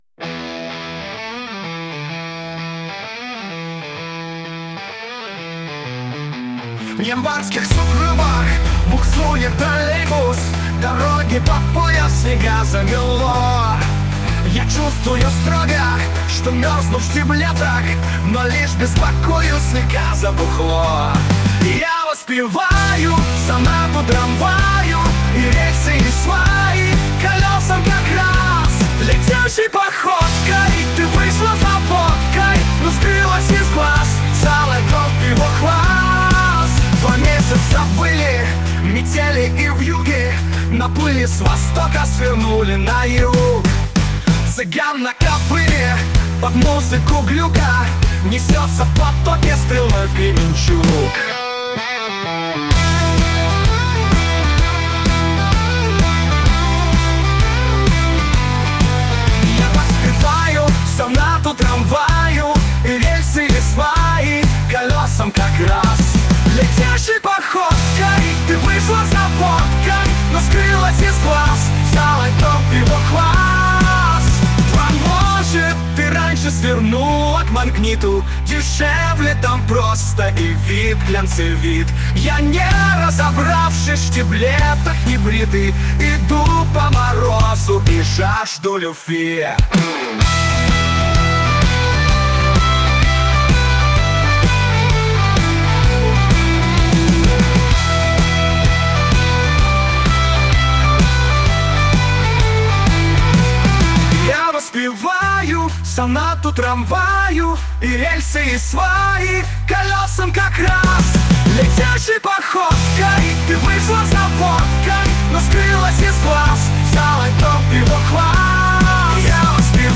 Дворовый романс, гаражный рок